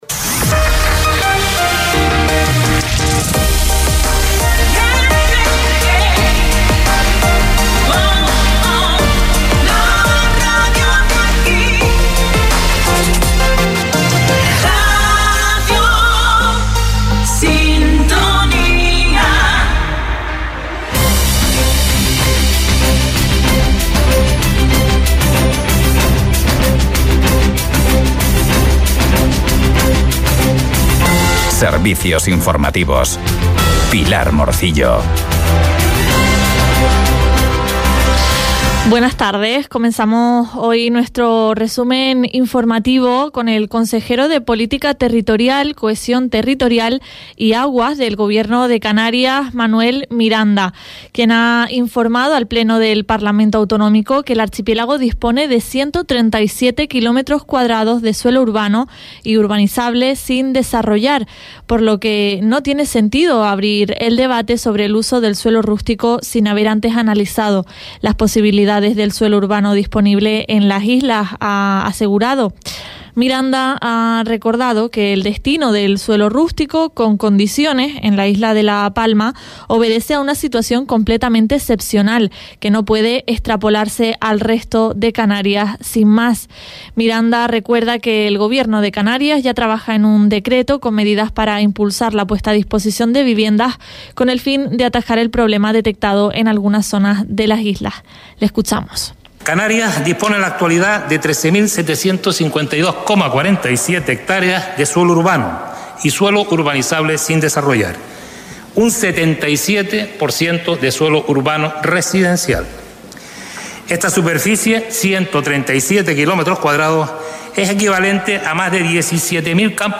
Informativos en Radio Sintonía – 07.02.24